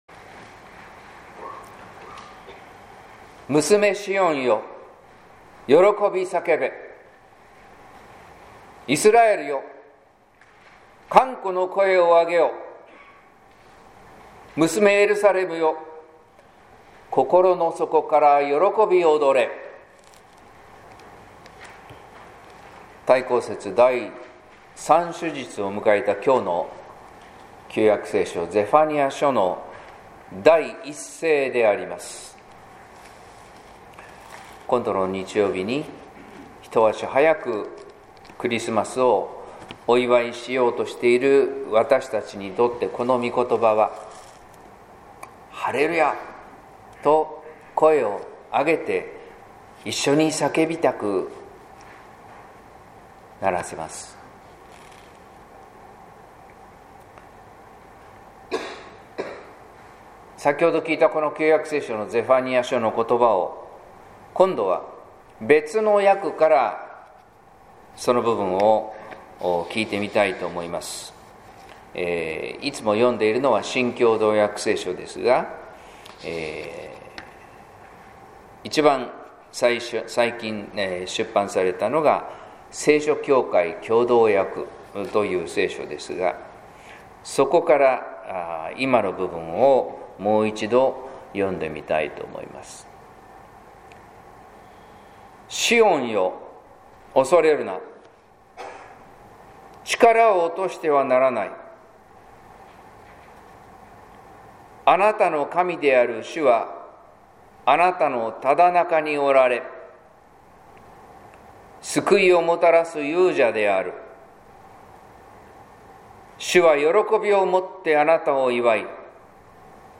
説教「来たる日に備え待ち望む」（音声版） | 日本福音ルーテル市ヶ谷教会